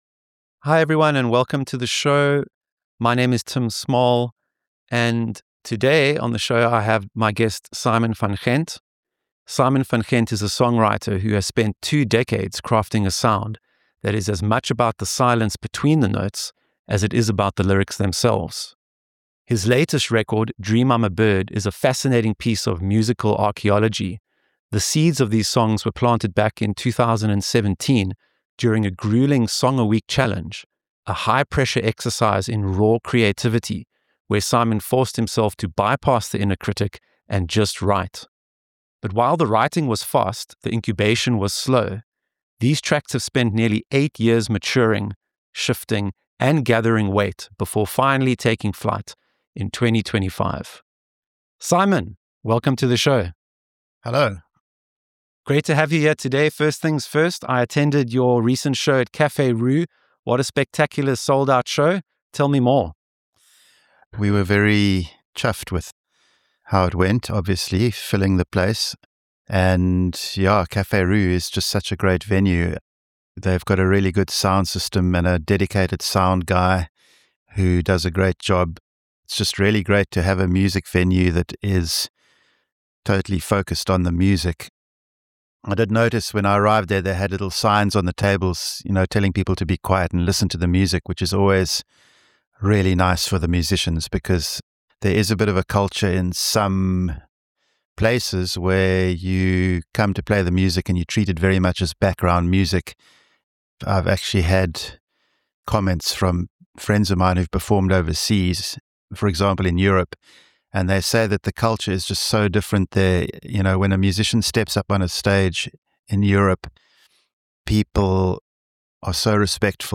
Dream I'm A Bird [interview]